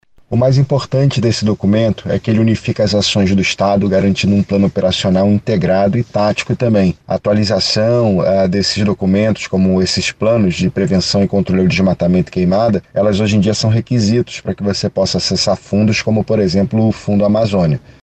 Sonora-2-Eduardo-Taveira-secretario-de-Meio-Ambiente-do-Amazonas.mp3